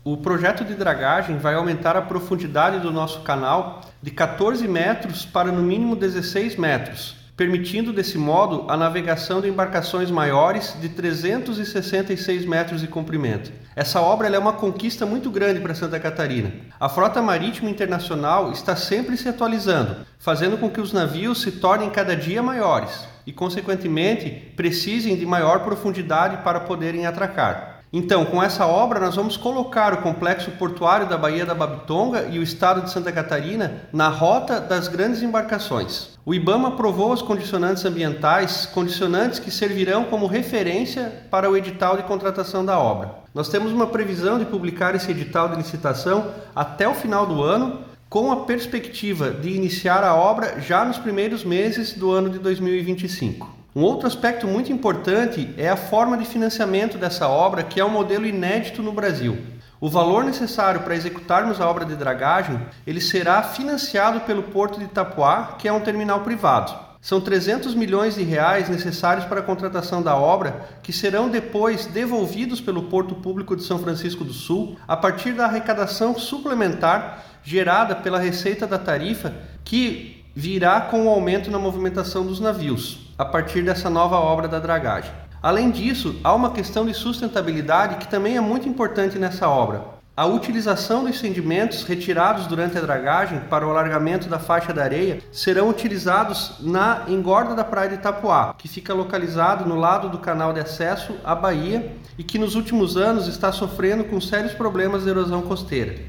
O presidente do Porto de São Francisco do Sul, Cleverton Vieira, explica a importância das ações que serão realizadas no terminal:
SECOM-Sonora-Presidente-Porto-Sao-Francisco-do-Sul-Licenca-Ibama.mp3